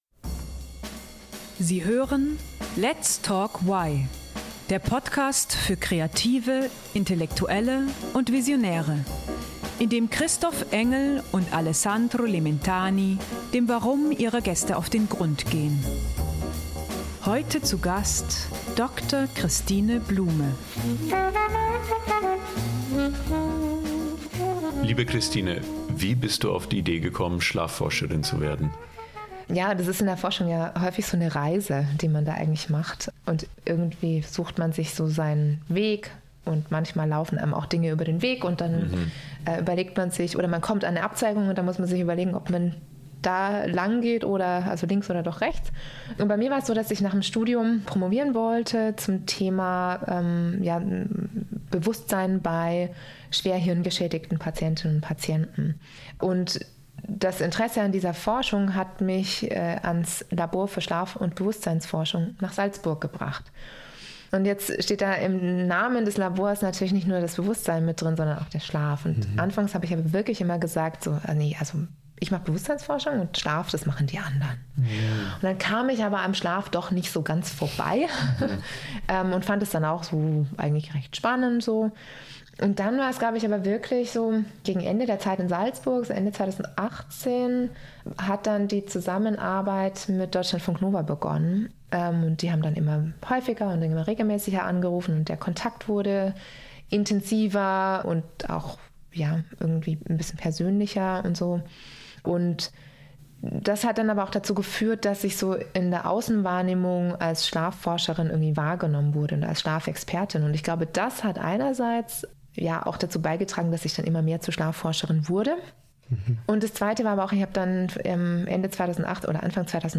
Das Interview wurde am 28.02.2025 aufgezeichnet.